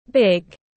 Big /bɪɡ/